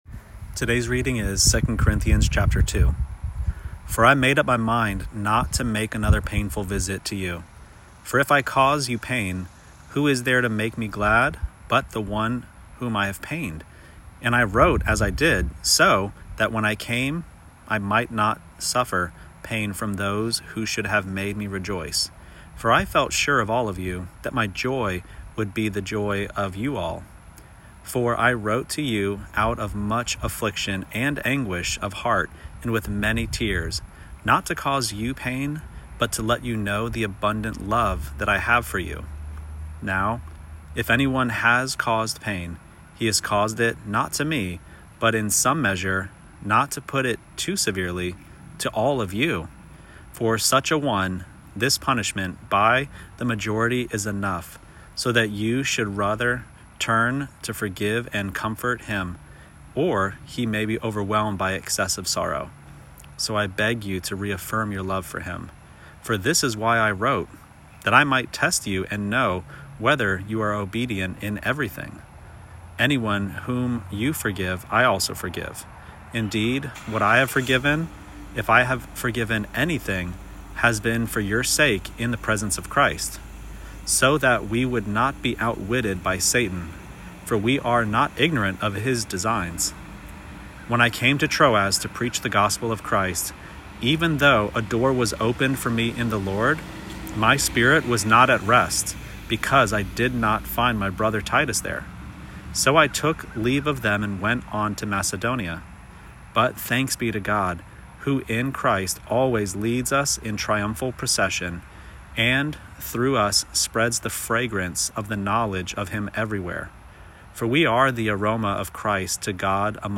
Daily Bible Reading (ESV)